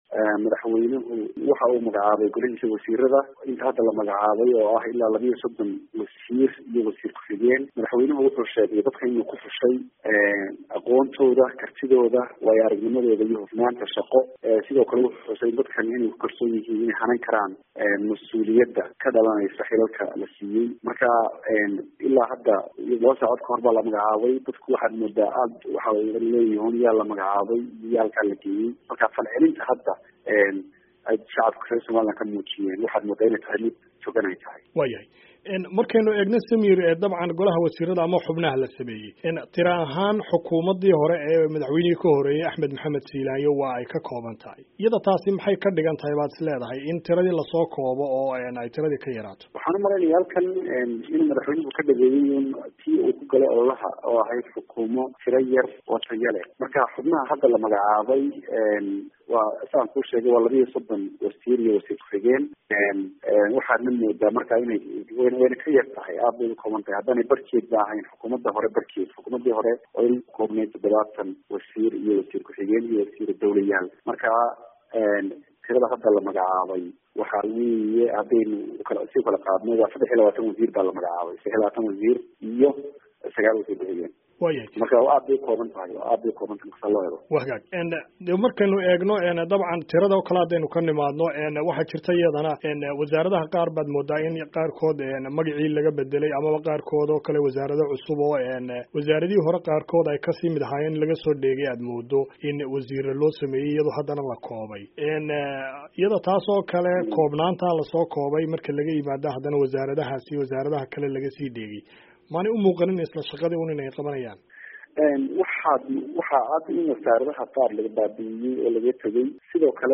Dhageyso wareysiga VOA